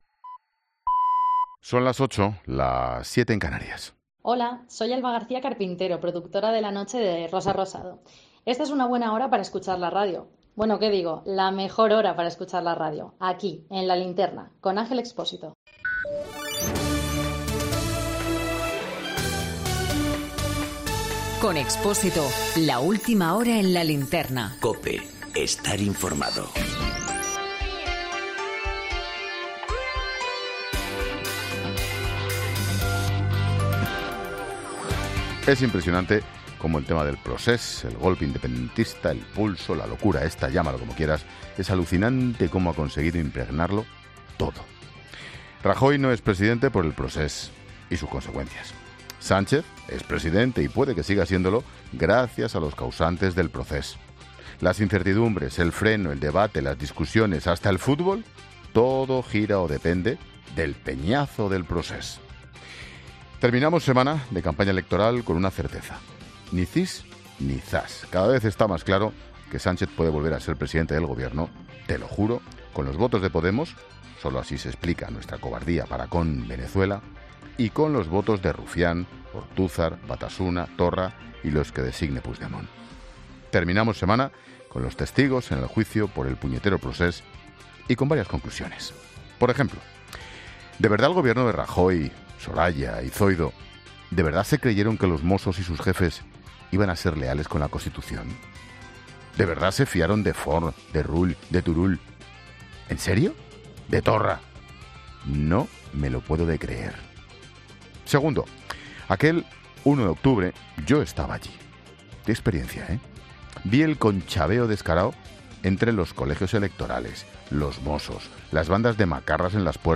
Monólogo de Expósito
El análisis de la actualidad de este viernes con Ángel Expósito en 'La Linterna'